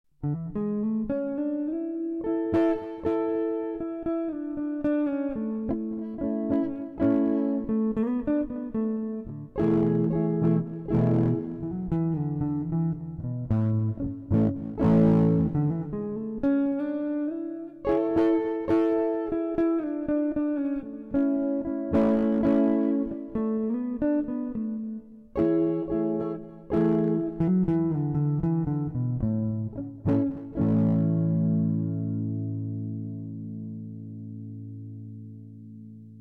So this is my first attempt at recording something Jazzy. There's some unwanted distortion in there and stuff so please ignore the quality of the recording....
jazz-attempt1.mp3